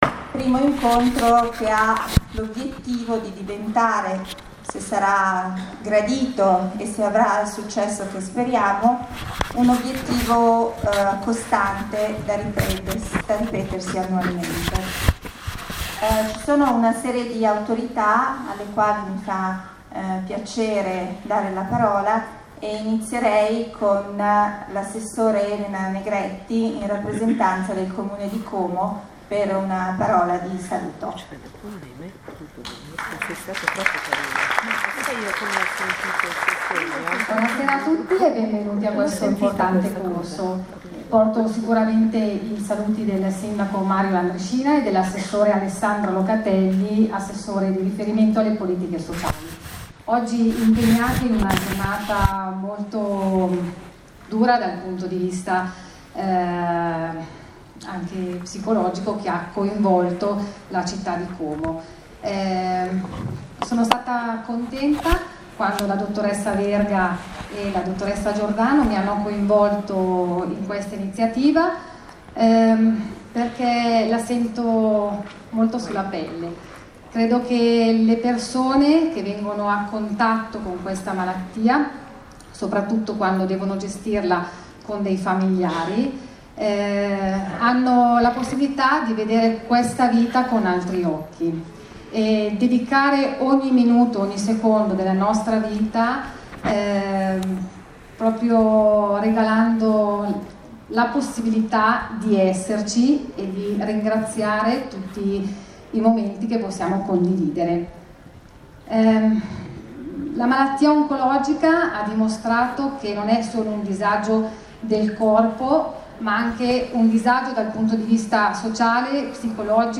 Quando la malattia oncologica entra nella nostra vita. Il valore e i contributi della PSICONCOLOGIA, incontro formativo a cura della ASST Lariana e Centro di Riferimento Oncologico “TULLIO CAIROLI”, 20 Ottobre 2017, Auditorium Camera di Commercio di COMO, Via G. Parini, 16.
AUDIO della presentazione: